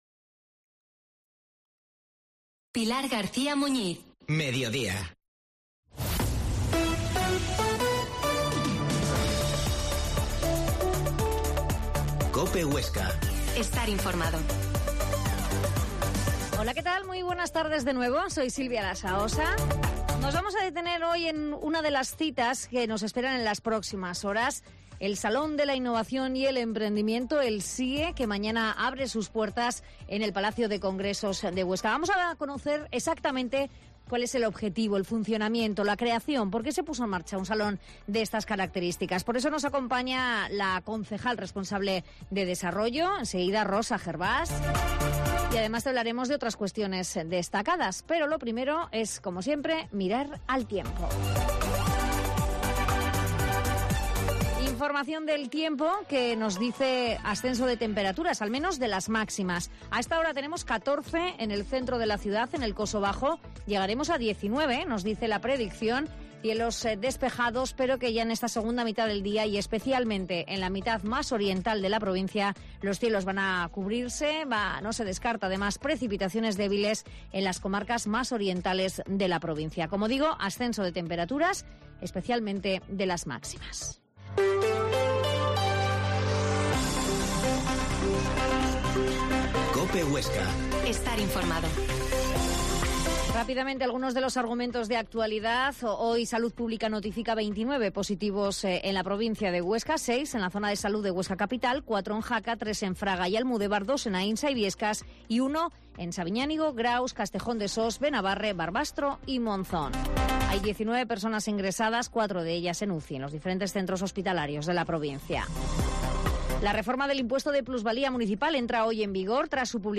Mediodía en Cope Huesca 13,50h. Entrevista a la concejal de Desarrollo, Rosa Gerbás
La Mañana en COPE Huesca - Informativo local Mediodía en Cope Huesca 13,50h.